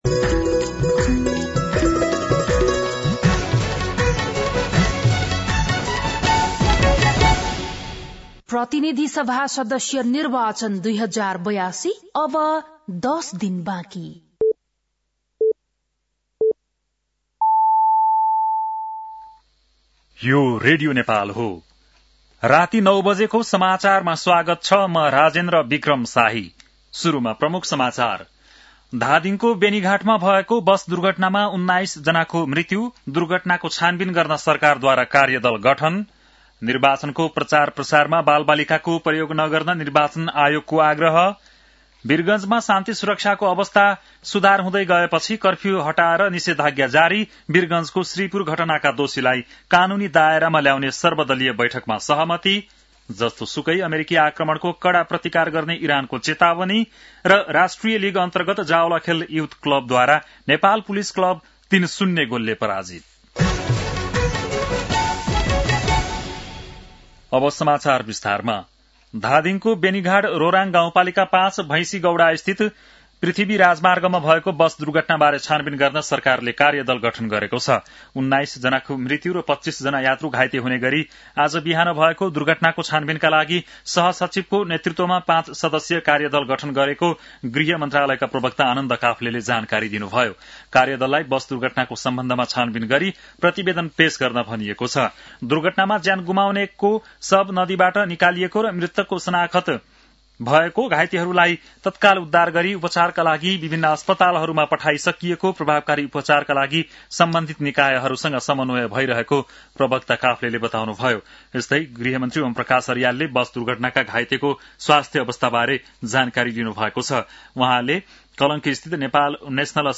बेलुकी ९ बजेको नेपाली समाचार : ११ फागुन , २०८२
9-PM-Nepali-NEWS-11-11.mp3